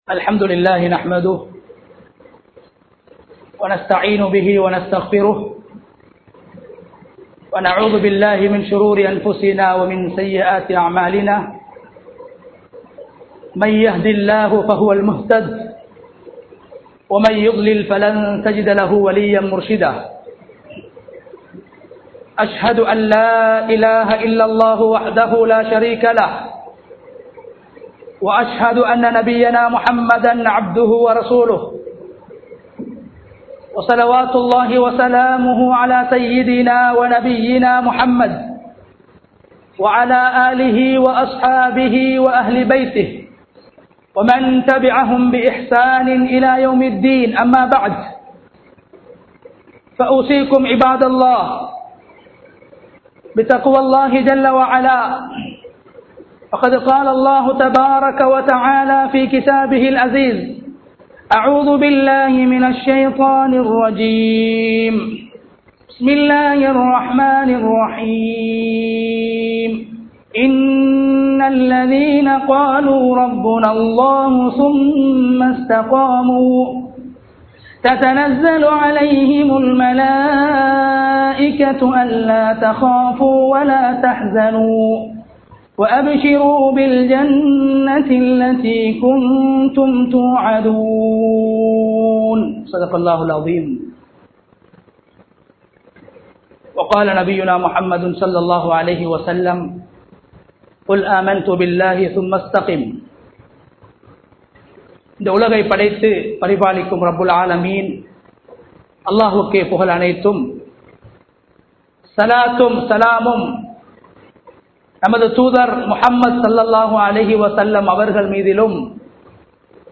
அமல்களில் உறுதியாக இருப்போம் | Audio Bayans | All Ceylon Muslim Youth Community | Addalaichenai
Munawwara Jumua Masjidh